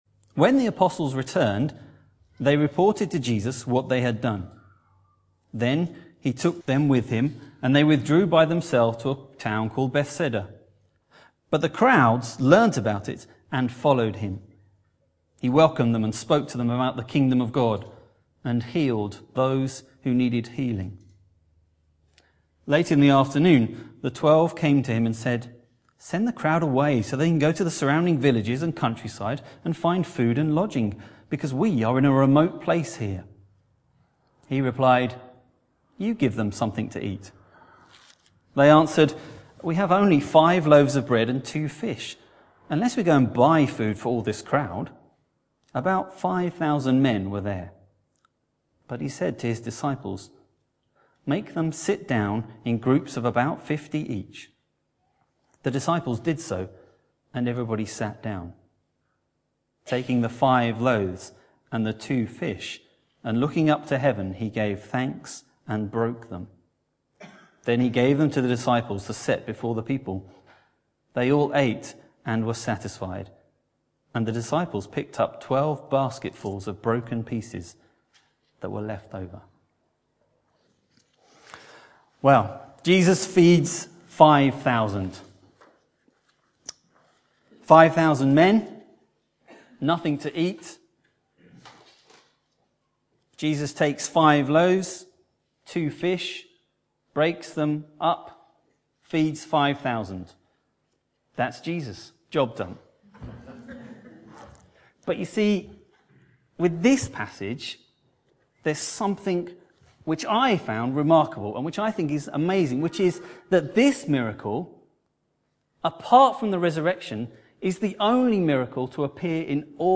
In the third of four sermons describing meals with Jesus we look at the feeding of 5,000 men plus women and children in a miraculous way by Jesus.